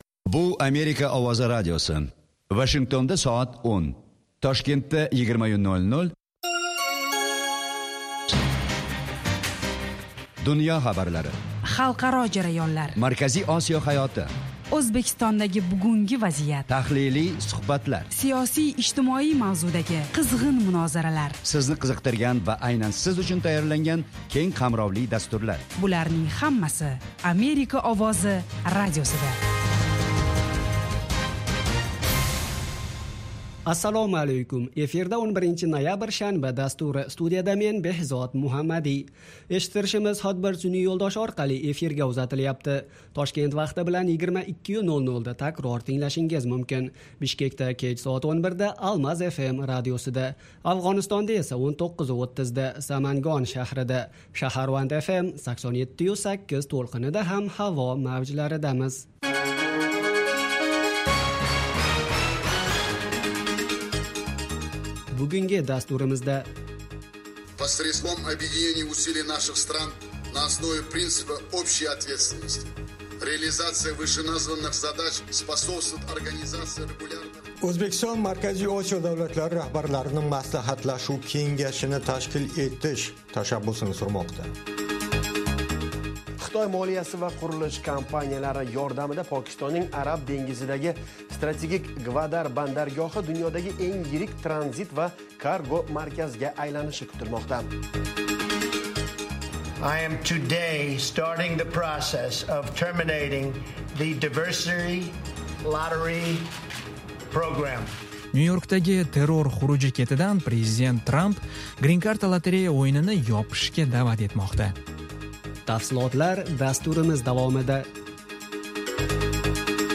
Toshkent vaqti bilan har kuni 20:00 da efirga uzatiladigan 30 daqiqali radio dastur kunning dolzarb mavzularini yoritadi. Xalqaro hayot, O'zbekiston va butun Markaziy Osiyodagi muhim o'zgarishlarni, shuningdek, AQSh bilan aloqalarni tahlil qiladi.